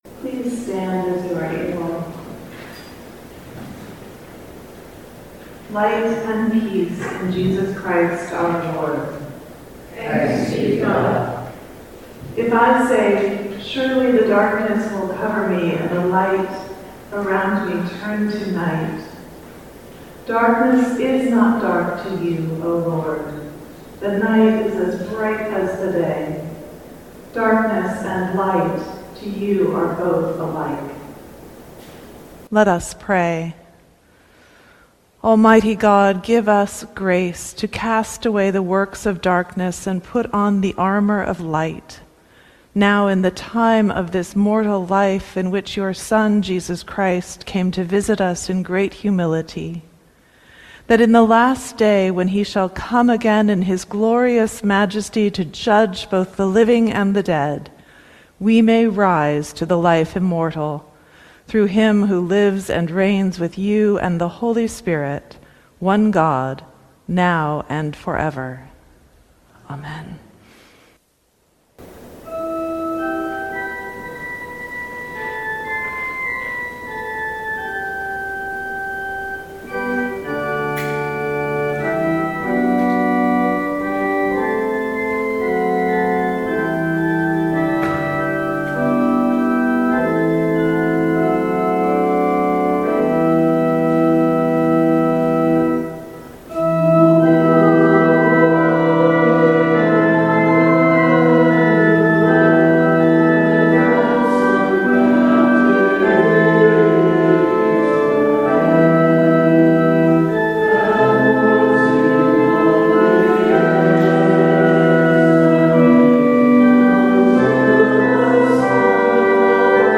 Audio recording of the 4pm service